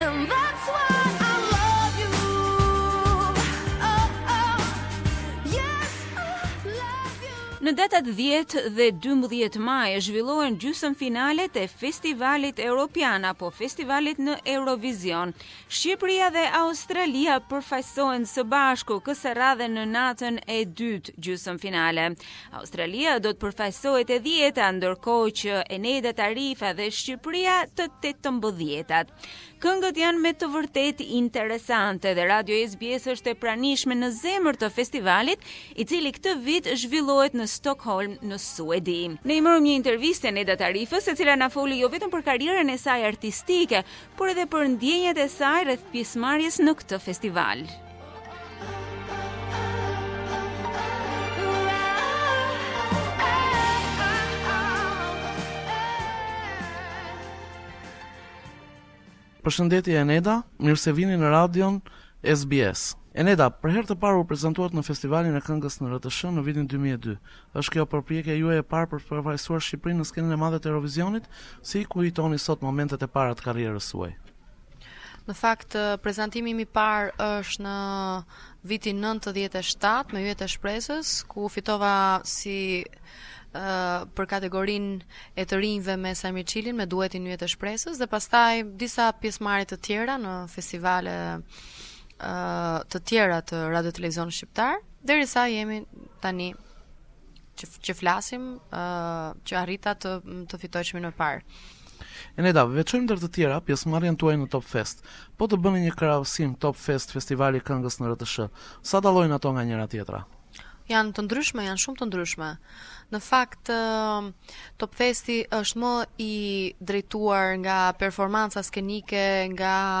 Eurovision Albania - Interview with Eneda TArifa